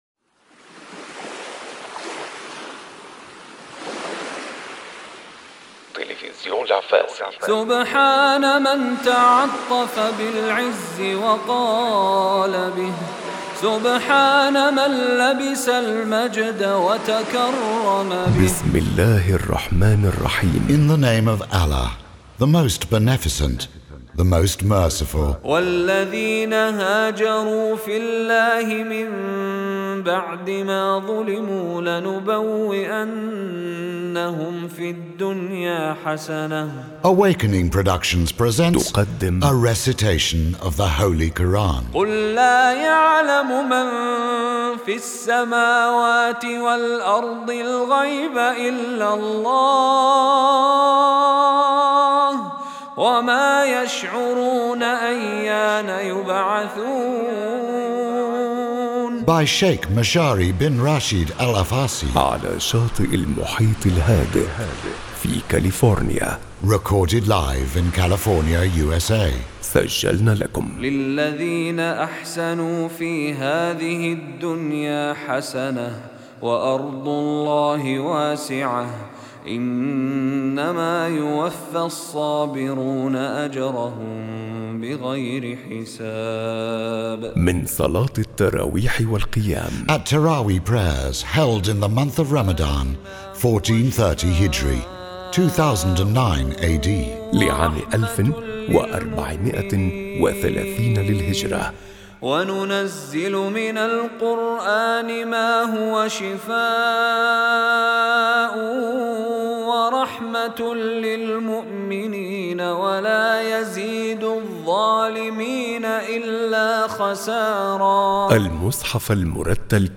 This collection consists of Mishary Rashid al Afasy during his 2010 Ramadan Tilaweh visit to America, who is a Kuwaiti national and a qari.
Please take time and reflect by listening to the wonderful recitations.